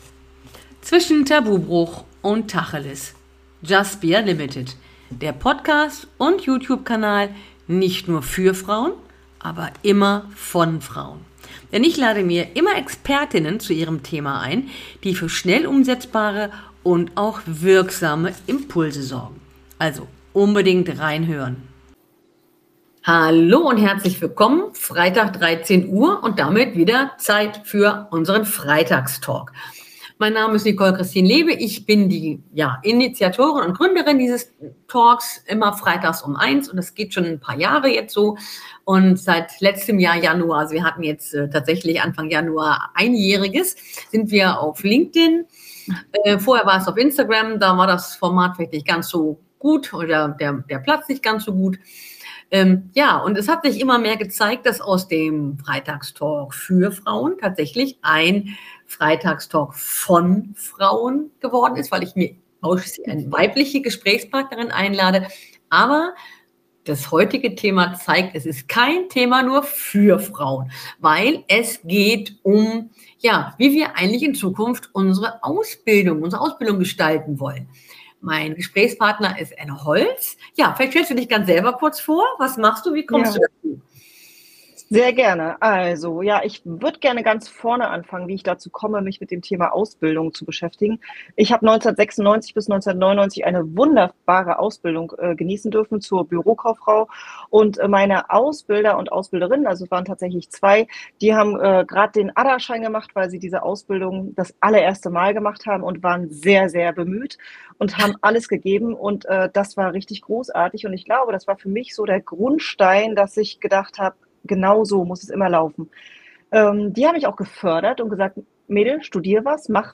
Und dieses Thema zeigt wieder einmal, dass wir hier zwar ein Talk VON Frauen sind, allerdings keineswegs nur FÜR Frauen.